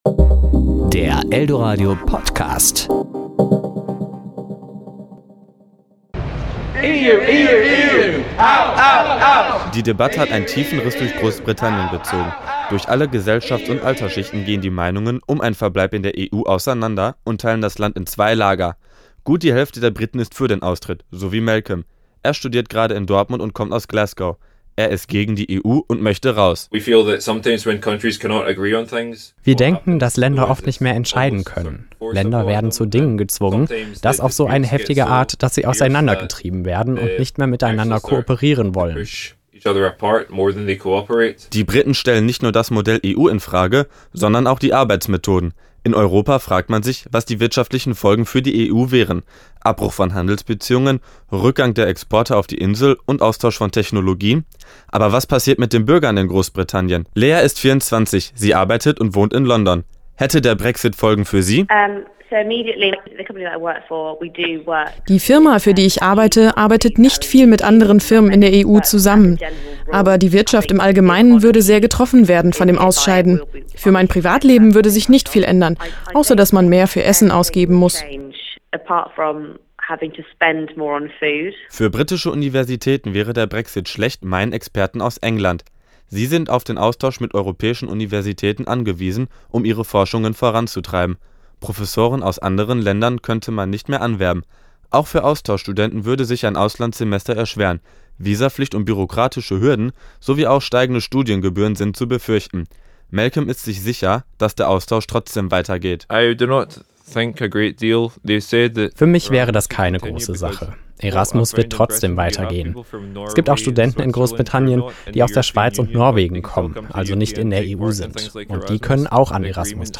hat mit zwei jungen Briten gesprochen.